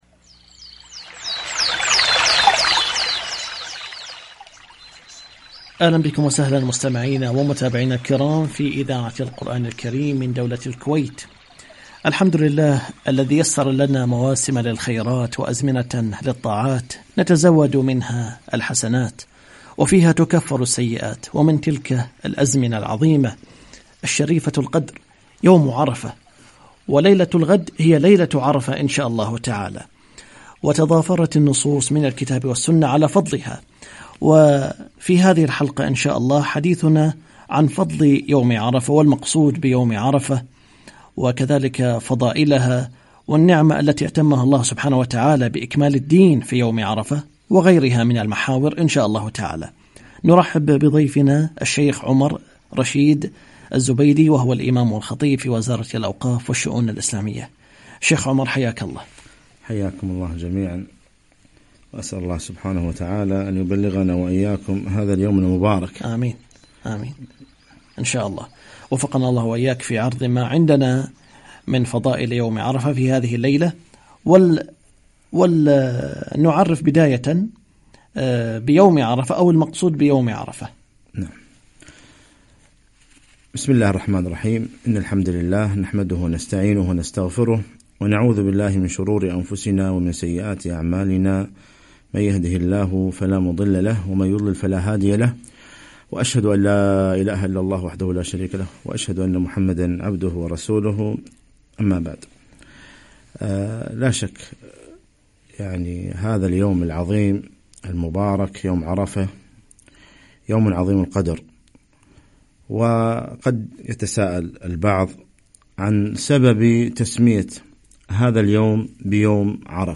يوم الثلاثاء 7 ذي الحجة 1438 إذاعة القرآن الكريم بدولة الكويت
فضل يوم عرفة - لقاء إذاعي عبر برنامج طريق الإيمان